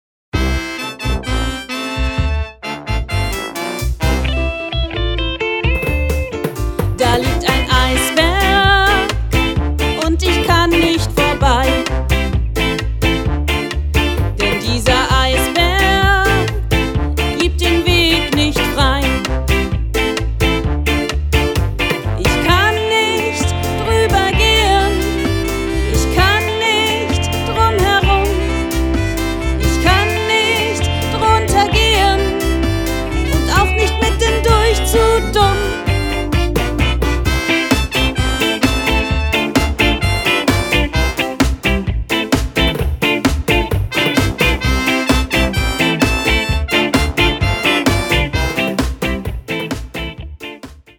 Ein Mutmachmusical für große und kleine Superhelden